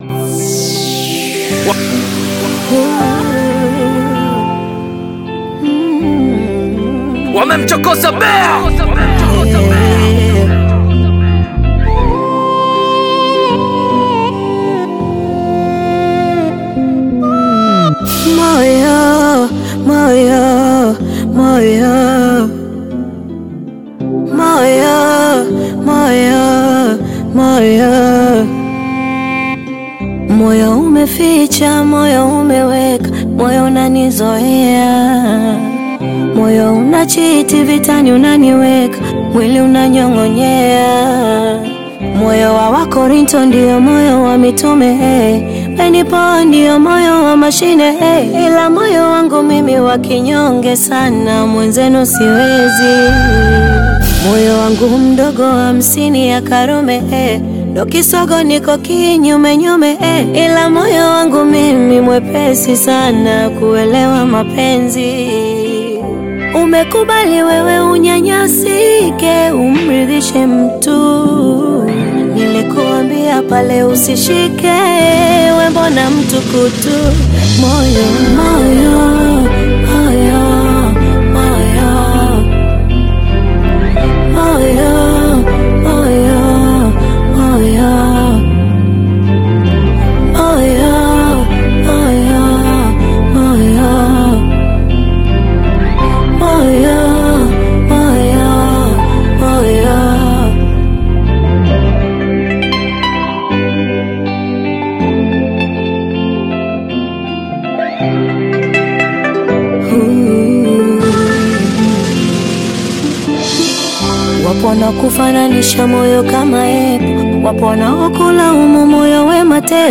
Bongo Flava
a love song
African Music